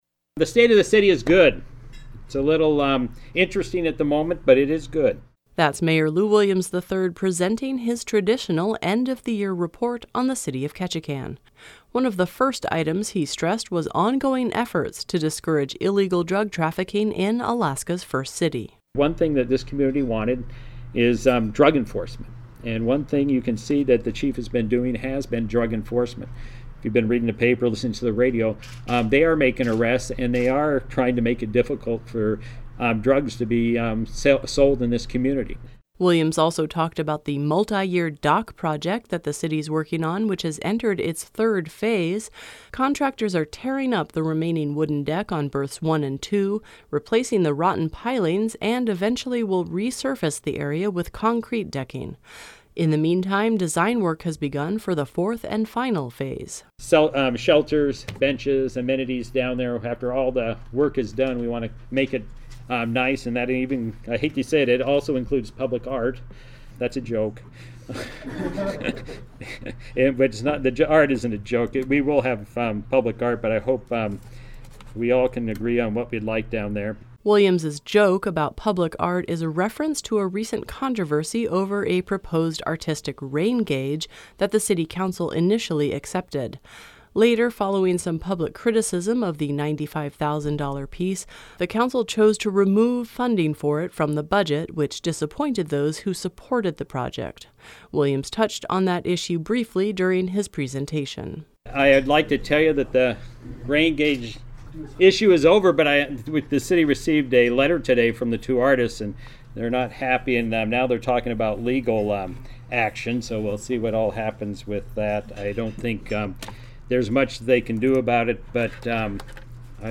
City of Ketchikan Mayor Lew Williams III gave his annual State of the City address last week during the Greater Ketchikan Chamber of Commerce lunch. He touched on a variety of concerns and projects, from pipe replacements to public art.
Mayor Lew Williams III speaks last week during the Chamber of Commerce lunch at Cape Fox Lodge.